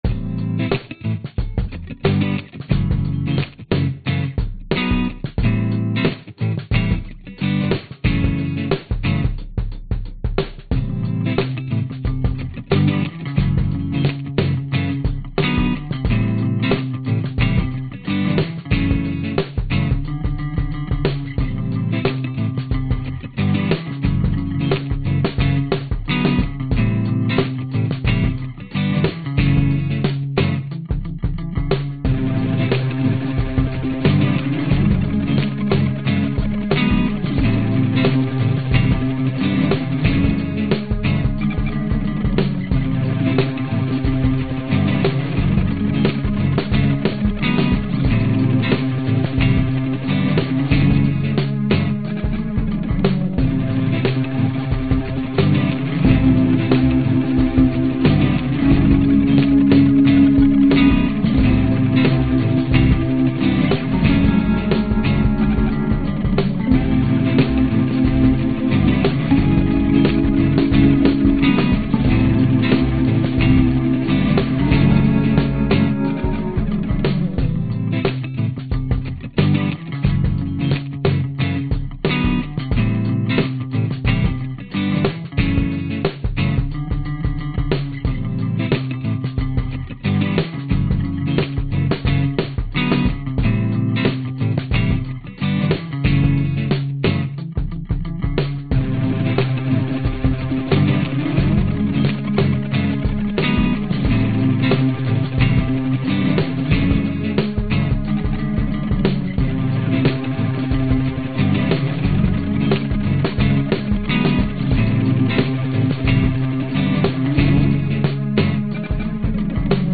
Tag: 吉他 失真 颤音吉他 摇滚 独立 器乐 电影音乐